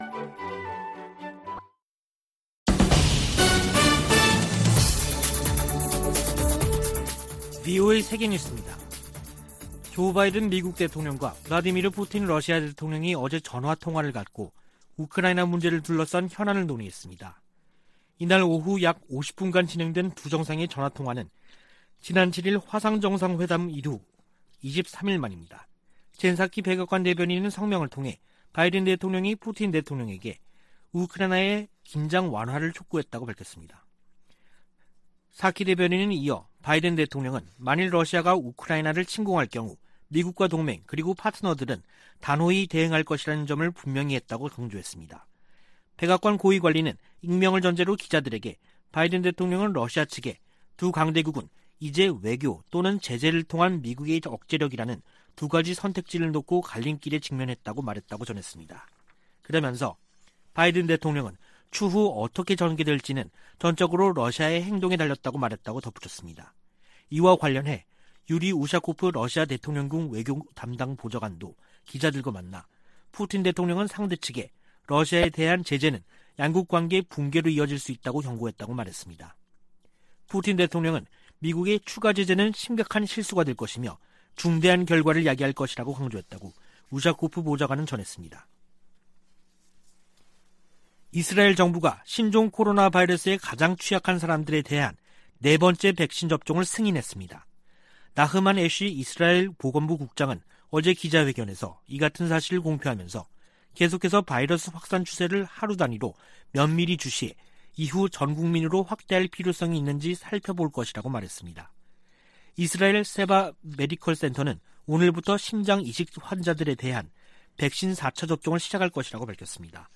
VOA 한국어 간판 뉴스 프로그램 '뉴스 투데이', 2021년 12월 31일 3부 방송입니다. 미국과 한국이 종전선언 문안에 합의한 것으로 알려지면서 북한과의 협의로 진전될지 주목됩니다. 2021년 미국은 7년 만에 가장 적은 독자 대북 제재를 부과했습니다. 북한의 곡물생산량이 지난해 보다 증가했지만 식량난은 여전한 것으로 분석됐습니다.